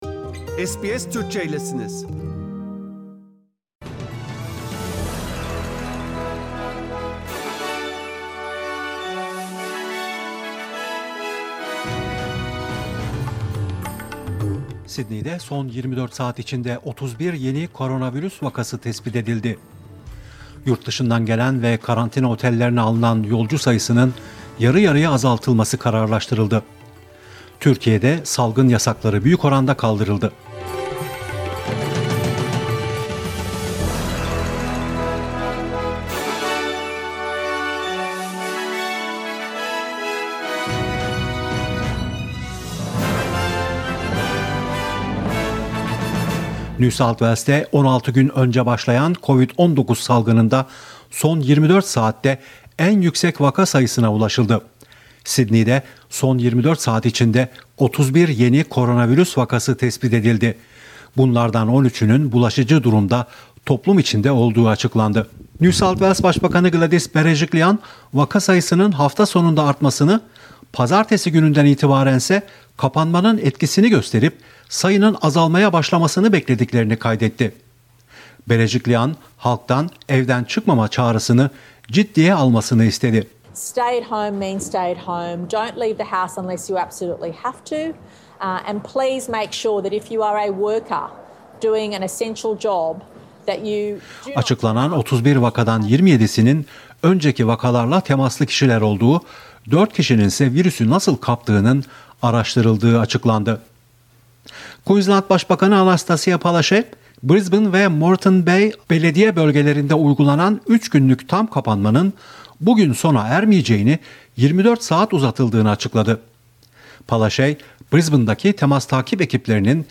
SBS Türkçe Haberler 2 Temmuz